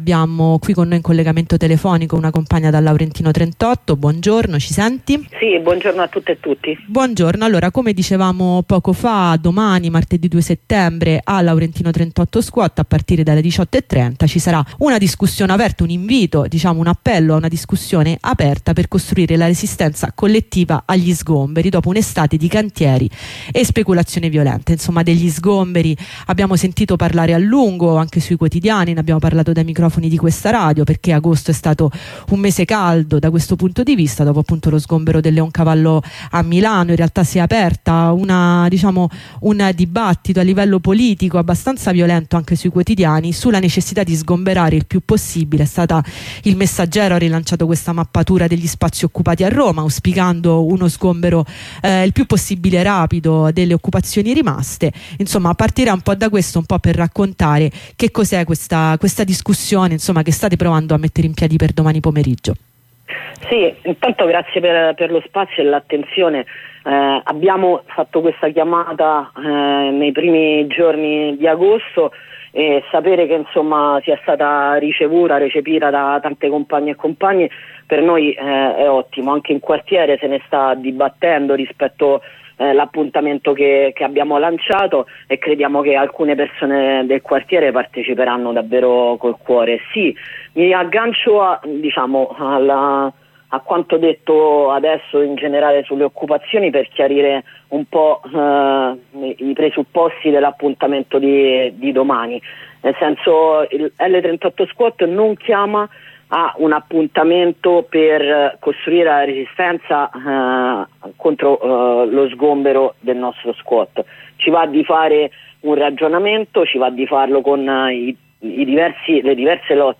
Apri il link seguente ed ascolta il nostro intervento a Radio Onda Rossa per ricordare a tuttx l’assemblea di domani, 2 settembre, a L38Squat.